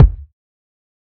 12kb - Murda Kick.wav